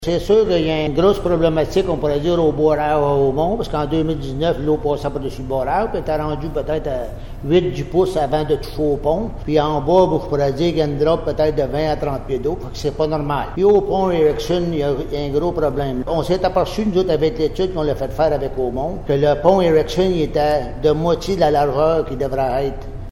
Pour tenter de pallier à la situation, la Municipalité de Déléage a fait une demande de subvention pour procéder à l’installation de deux ponceaux dans le secteur. Voici les propos du maire de Déléage Raymond Morin :